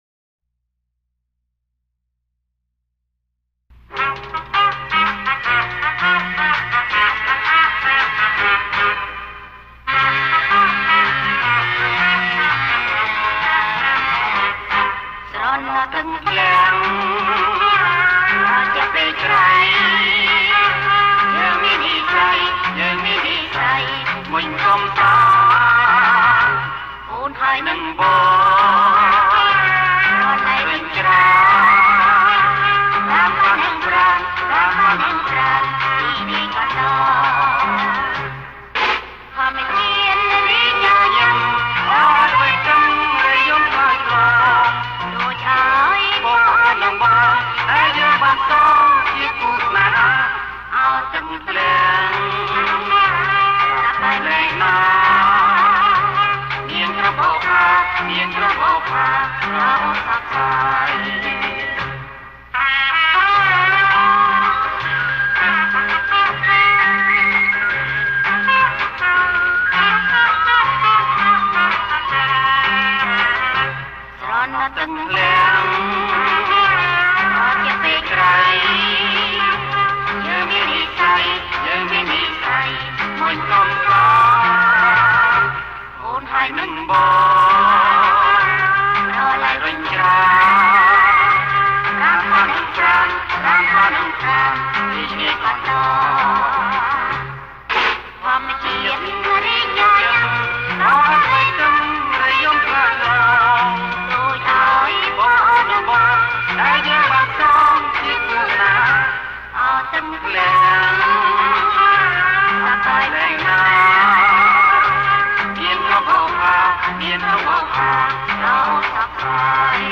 • ប្រគំជាចង្វាក់ Bossa nova